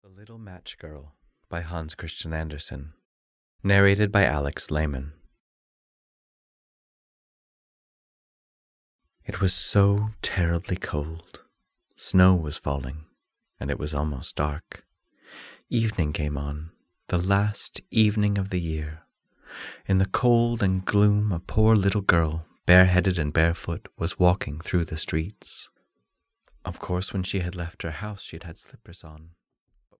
The Little Match Girl (EN) audiokniha
Ukázka z knihy